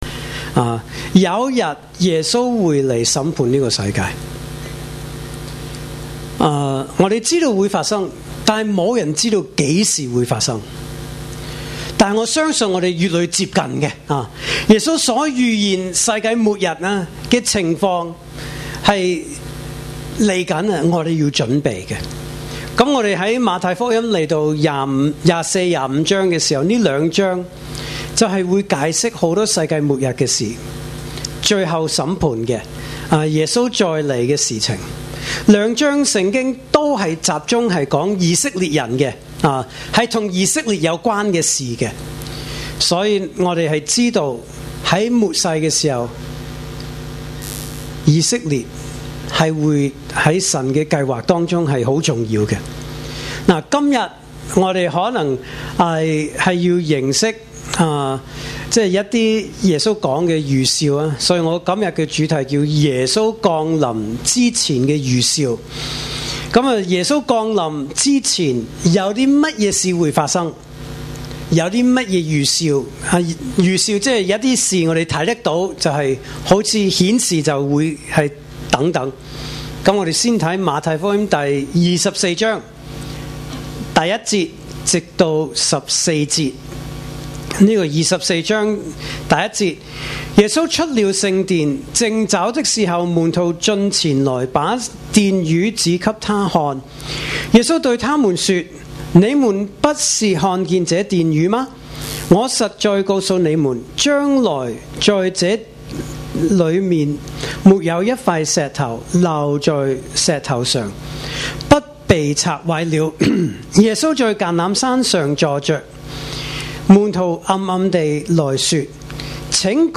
來自講道系列 "解經式講道"